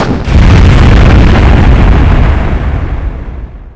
Missile sounds
missile 4.wav
They are really sharp, but I like them.
missile_4_179.wav